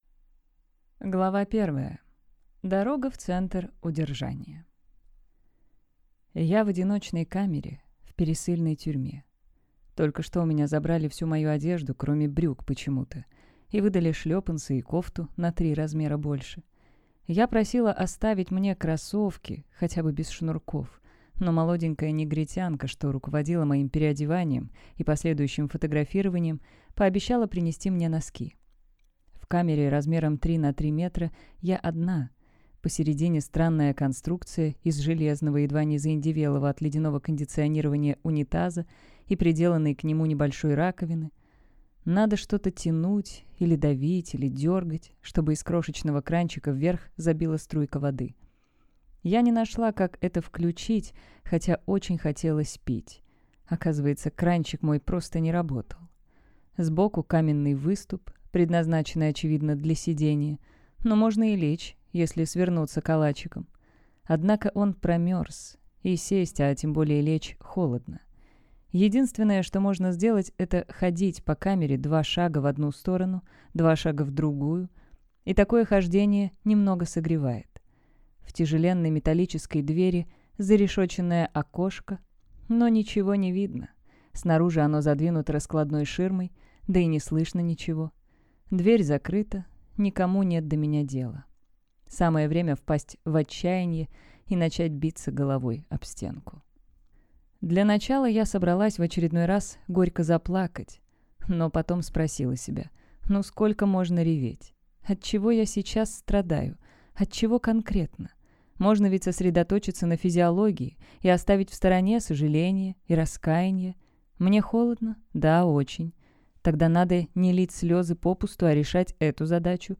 Аудиокнига Блондинка в американской тюрьме | Библиотека аудиокниг
Прослушать и бесплатно скачать фрагмент аудиокниги